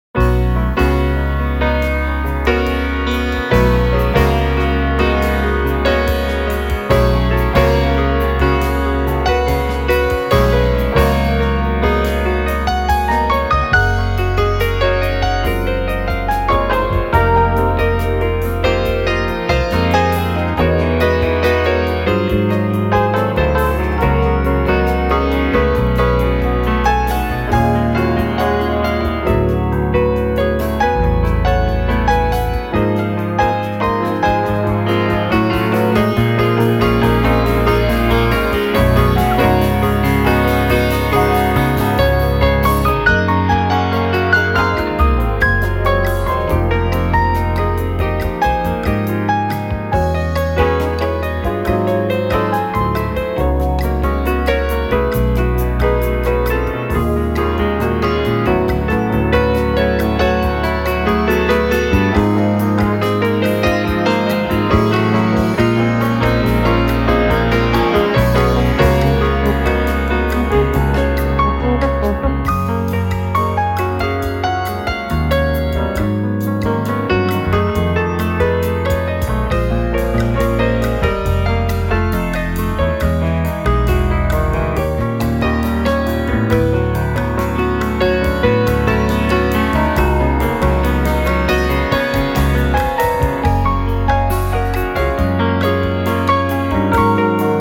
a peaceful and jazzy album